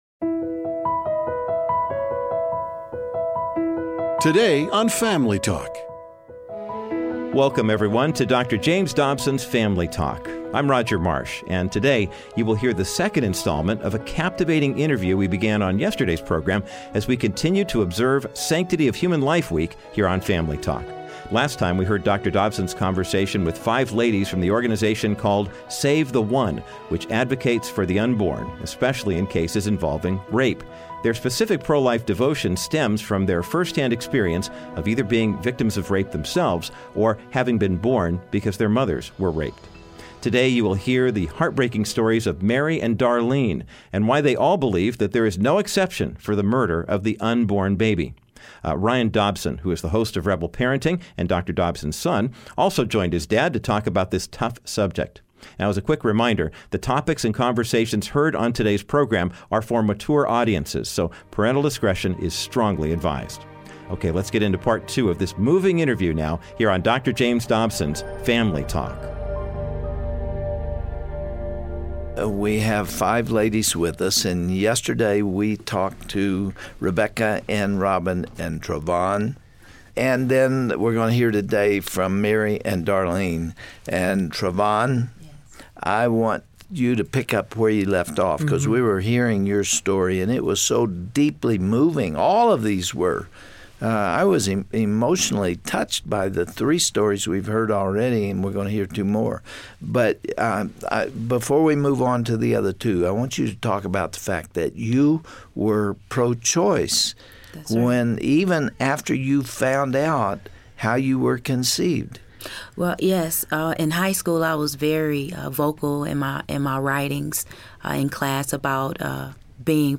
Today Family Talk will bring you part two of the moving conversation that began yesterday with the women from the organization called Save The 1. On this edition, they will continue to share their unbelievable testimonies and why they advocate so fervently for the unborn child, especially in instances of rape.
Host Dr. James Dobson